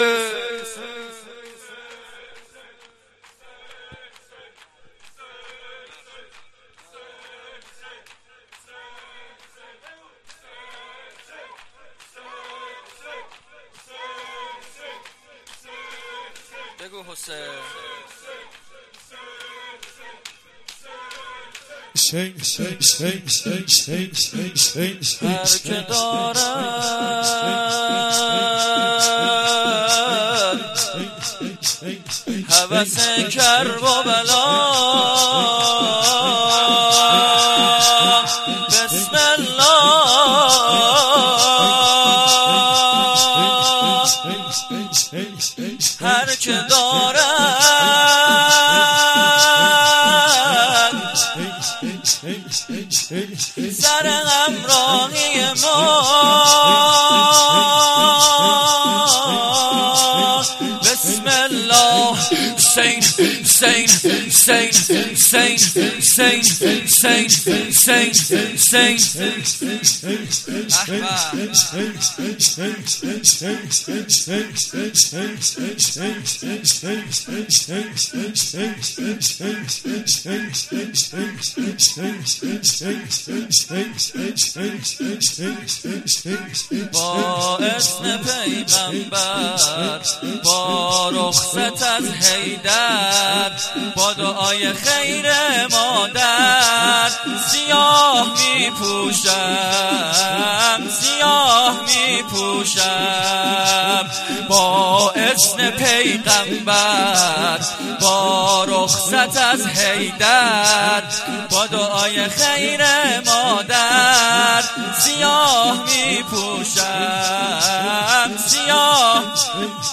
مراسم شب اول محرم 1395
سخنرانی شب اول محرم روضه حضرت مسلم-حضرت زهرا